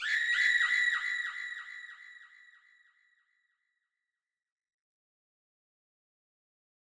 SouthSide Chant (61)(1).wav